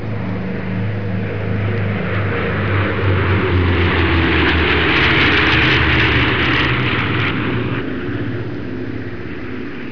دانلود آهنگ طیاره 27 از افکت صوتی حمل و نقل
جلوه های صوتی
دانلود صدای طیاره 27 از ساعد نیوز با لینک مستقیم و کیفیت بالا